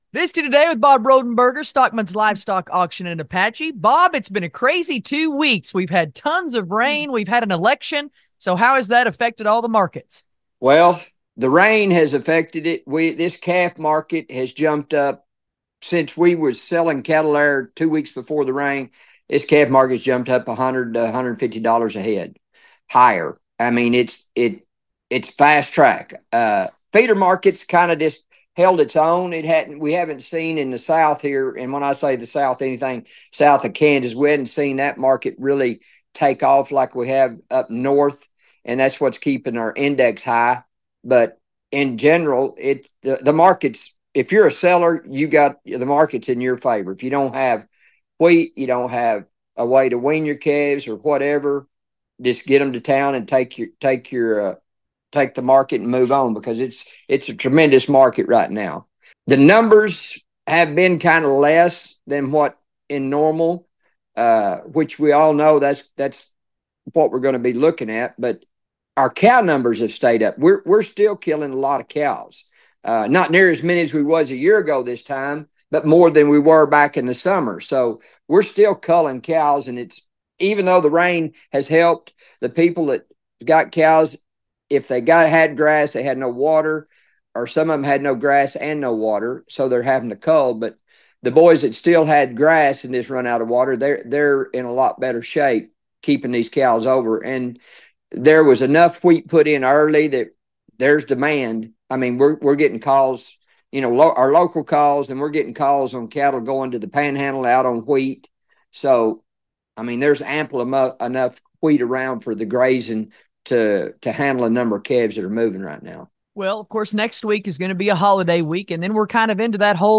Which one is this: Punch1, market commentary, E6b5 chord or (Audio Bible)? market commentary